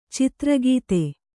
♪ citragīte